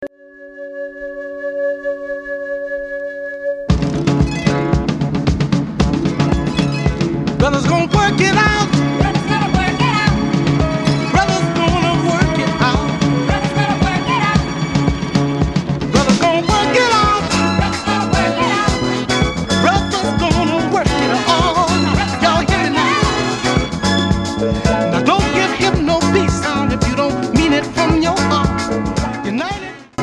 a wicked boogie number
a funk classic